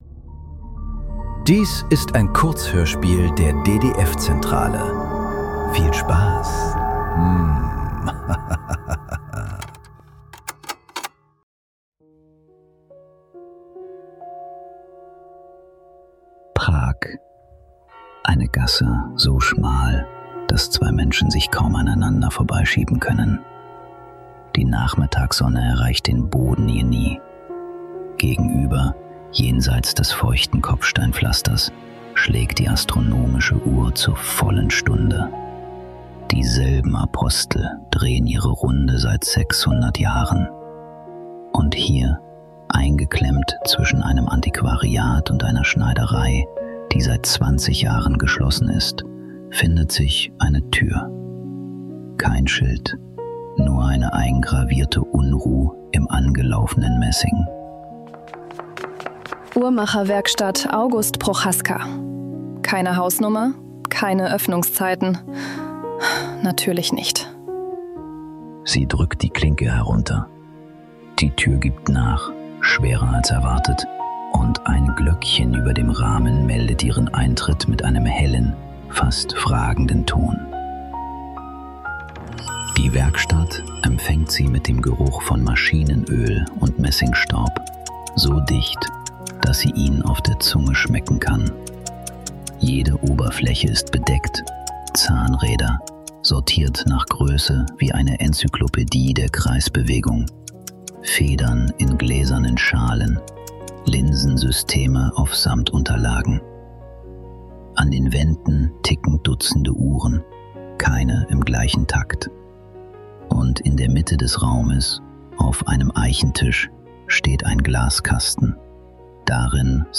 Der letzte Zug ~ Nachklang. Kurzhörspiele.